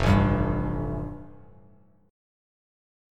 Fadd9 chord